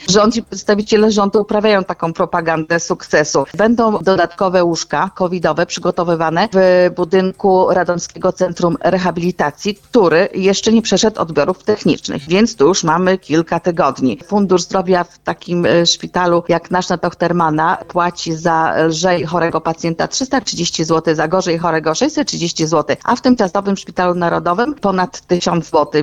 Za zapewnienie kadry medycznej w placówce odpowiada lecznica patronacka – Radomski Szpital Specjalistyczny, który nie dysponuje zwyżką personelu – podkreśla w Mocnej Rozmowie Lasota.